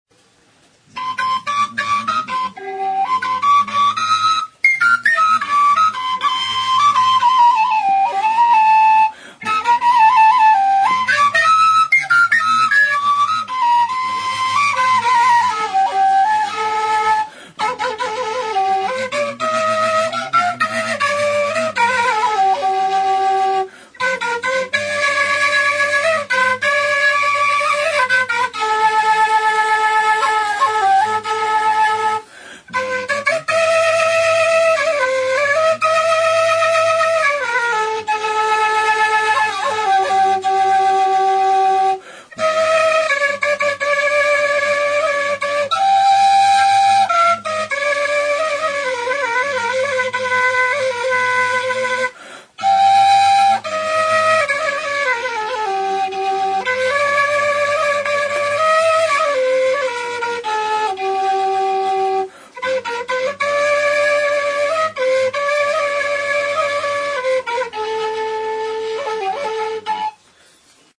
Zurezko ahokodun flauta zuzena da. Doinuak emateko 6 zulo ditu aurrealdean.
Instruments de musique: ERDÉLYI FURULYA Classification: Aérophones -> Flûtes -> Á bec (á deux mains) + kena